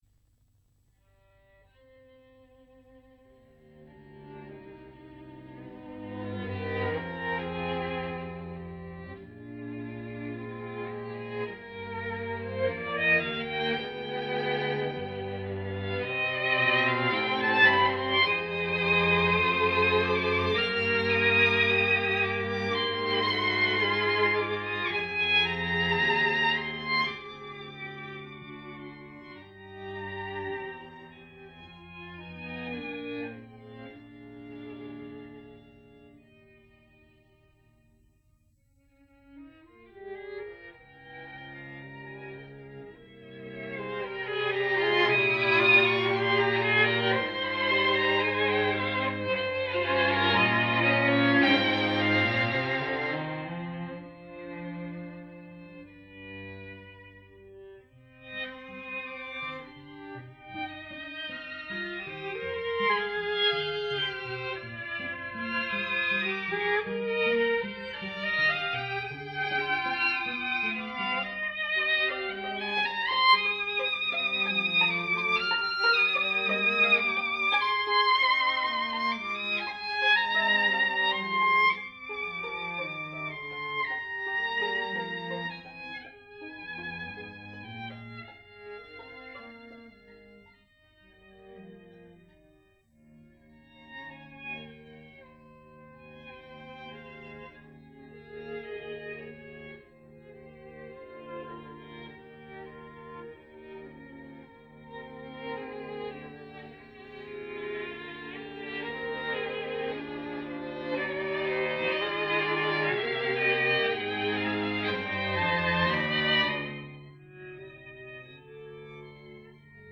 21/03/2012 10:58 Archiviato in: Live recording
Salone delle Manifestazioni del Palazzo regionale, Novembre 1993
Langsamersatz per quartetto d’archi (1905)